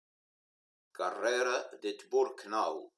Prononcer "La Carrère", "La Carrèro"...